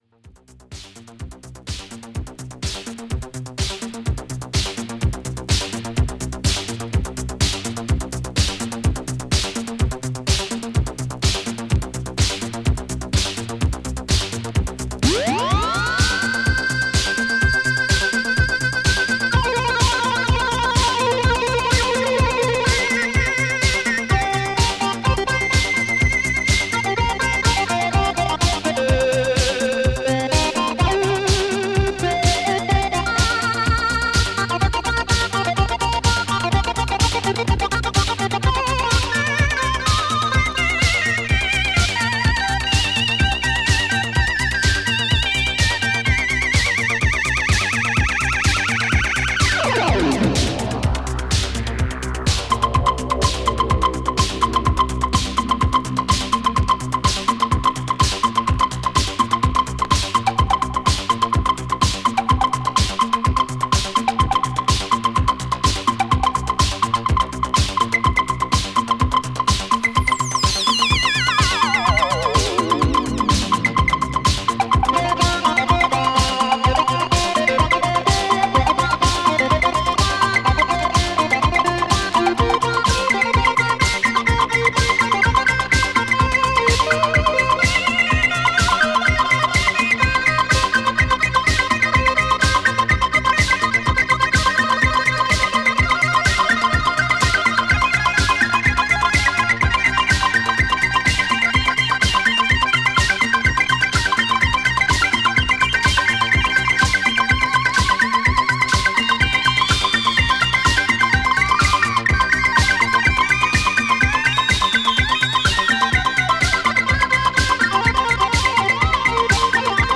DANCE
細かな擦りの為チリチリ個所あります。